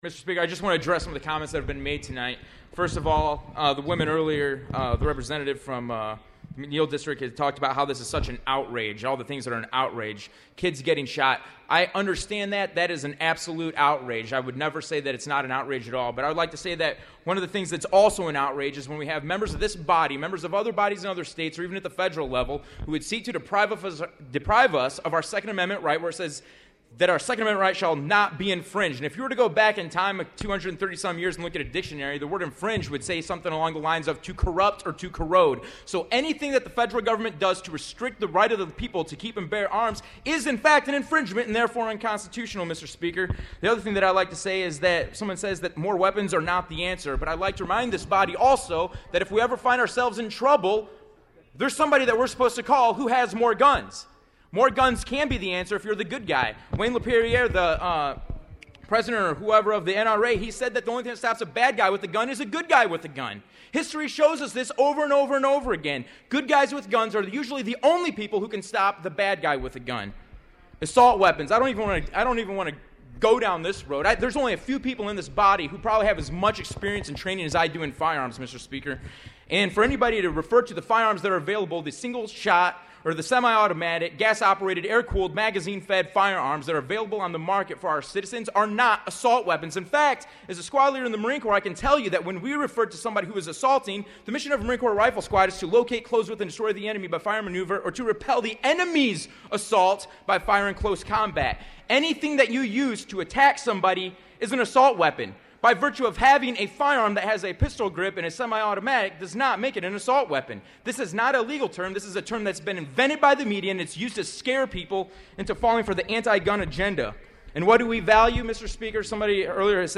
AUDIO:  Curtman’s floor remarks (3:27)